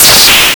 DataCorruption.wav